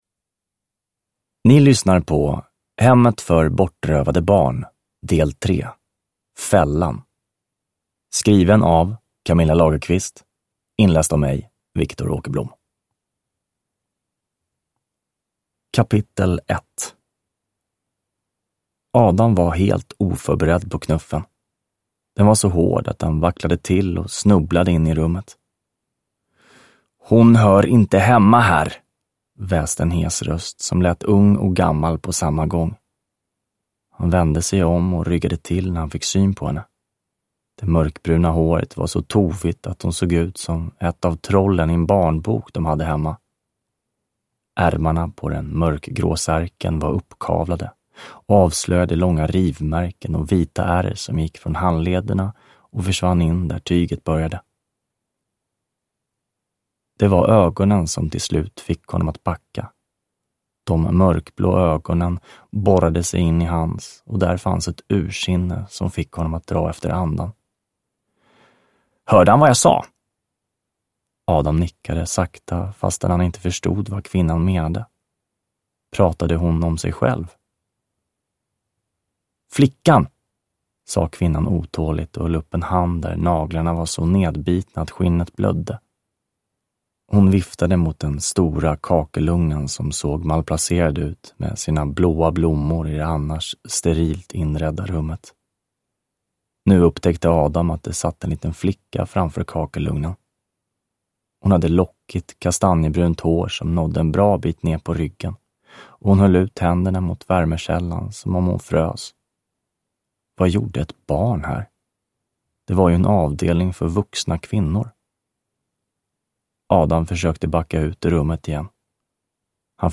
Fällan – Ljudbok – Laddas ner